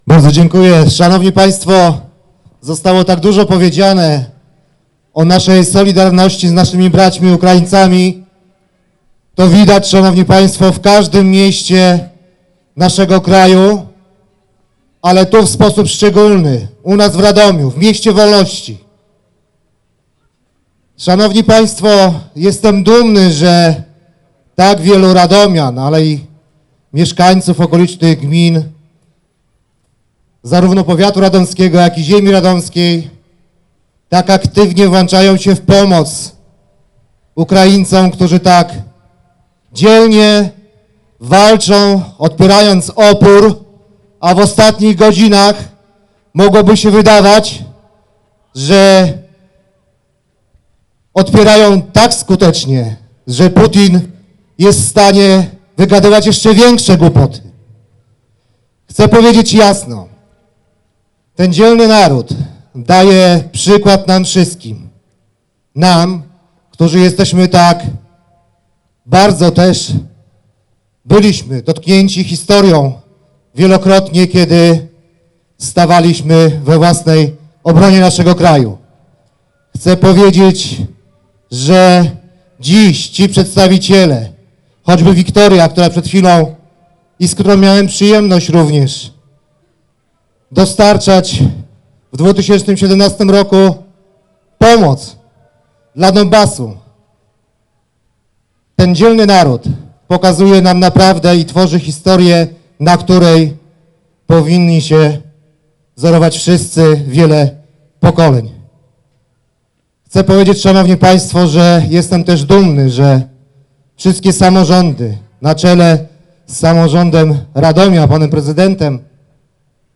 Radomianie dla Demokracji i radomska Platforma Obywatelska zorganizowali Wiec Solidarności z Ukrainą.
Na wiecu obecny był wicemarszałek województwa  mazowieckiego, Rafał Rajkowski: